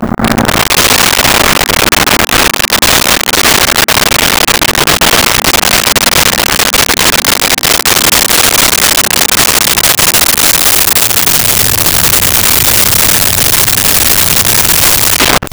Muscle Car In Idle Off 01
Muscle Car In Idle Off 01.wav